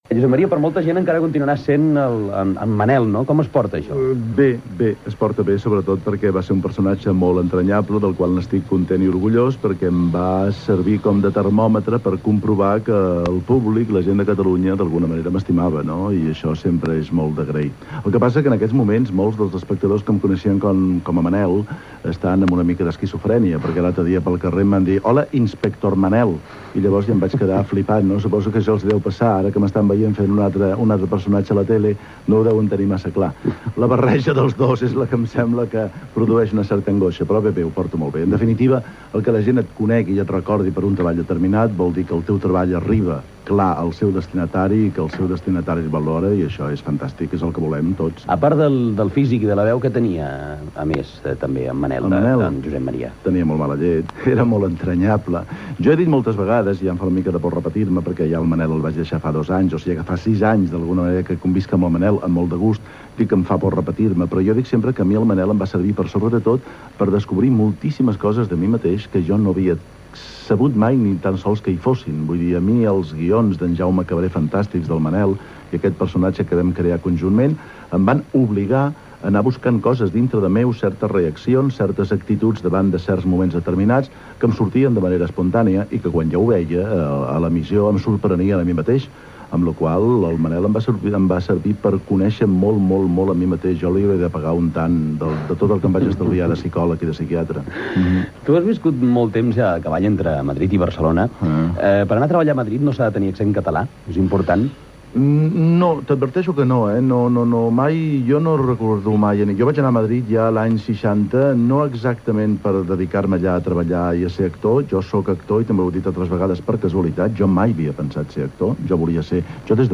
Entrevista a l'actor Josep Maria Pou sobre la seva feina recent a la televisió
Entreteniment